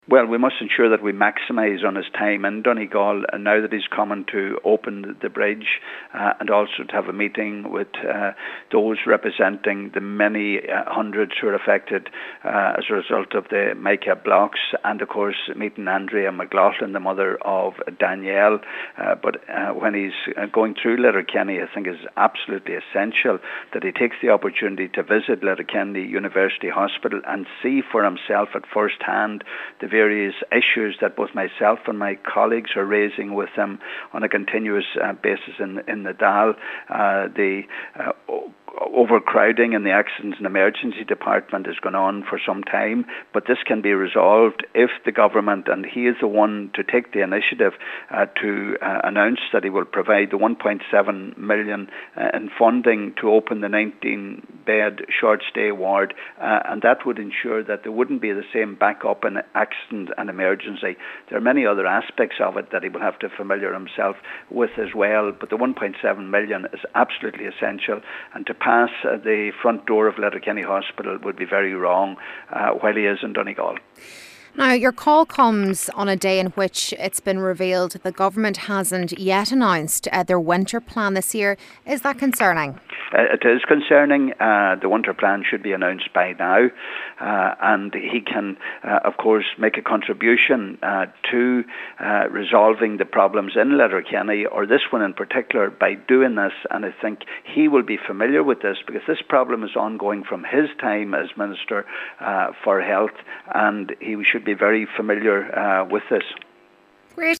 Deputy Gallagher says furthermore, Mr. Varadkar should sanction the reopening of the short stay ward while he’s here: